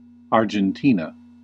Ääntäminen
Synonyymit Argentine Ääntäminen US UK : IPA : /ˌɑː.dʒənˈtiː.nə/ US : IPA : /ˌɑɹ.dʒənˈti.nə/ Lyhenteet ja supistumat (laki) Arg.